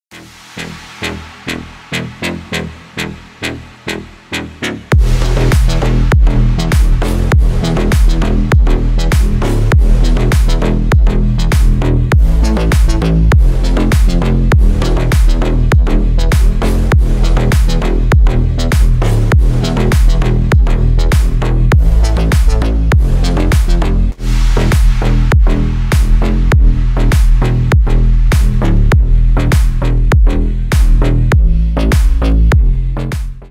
Танцевальные
клубные # громкие # без слов